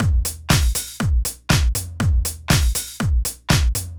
Drumloop 120bpm 10-A.wav